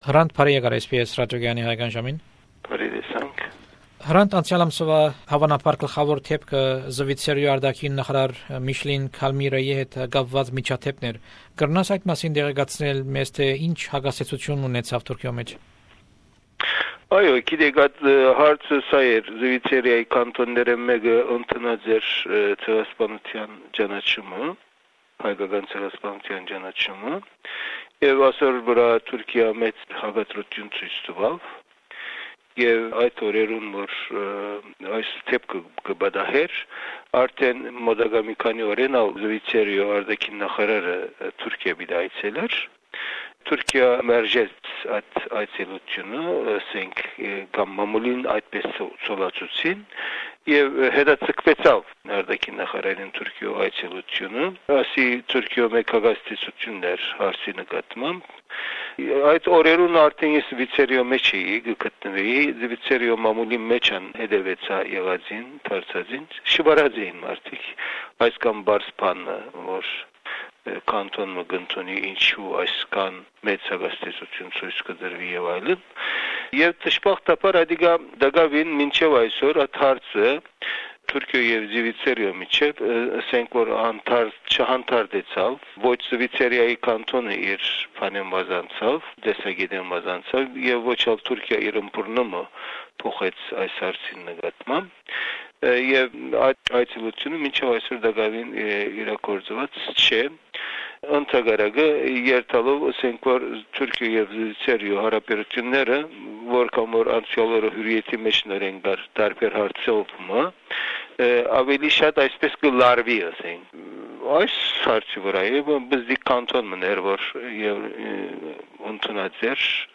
On 10th anniversary of the assassination of Hrant Dink, we bring you the interviews he gave to SBS Radios Armenian program. This is interview No. 6. Among other subjects Hrant talks about an article he wrote against the fake news that during WWI, Turks didnt kill Armenians on the contrary Armenians killed Turks.